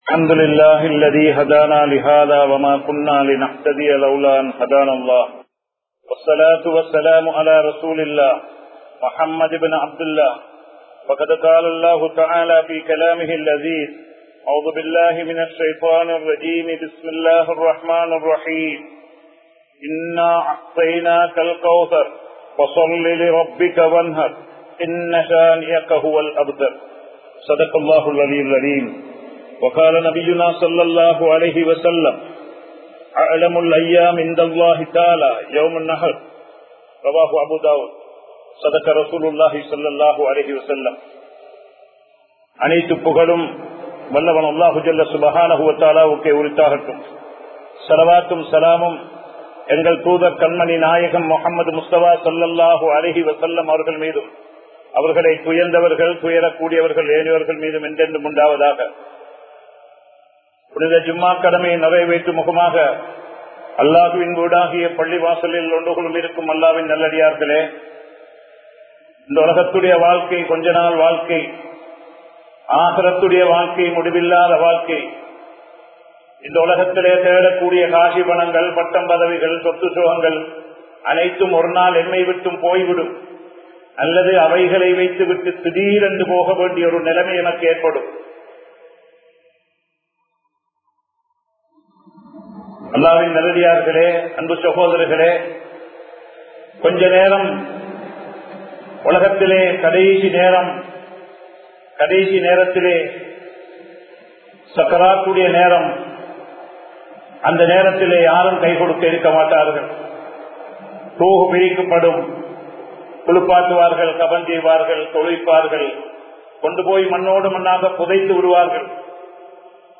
துல்ஹிஜ்ஜாவின் ஆரம்ப 10 நாட்களின் சிறப்புகள் | Audio Bayans | All Ceylon Muslim Youth Community | Addalaichenai
Kollupitty Jumua Masjith